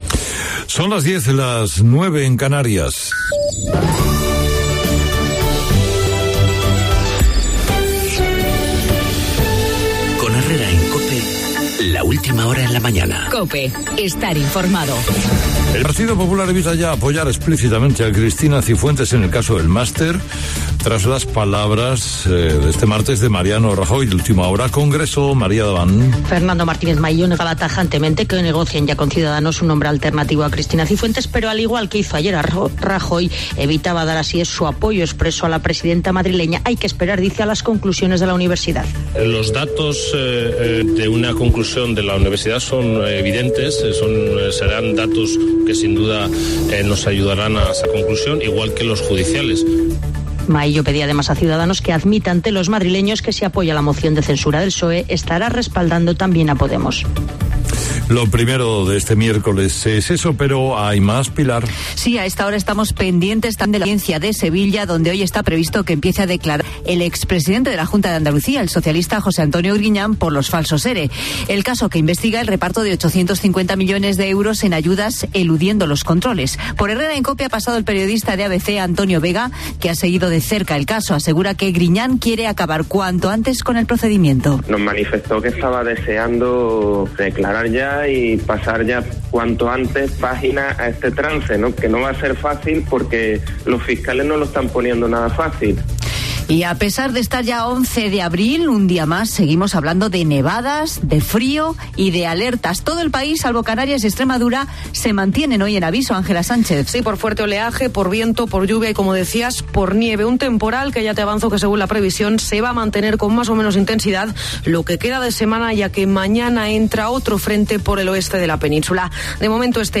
Boletín informativo de COPE Castilla-La Mancha.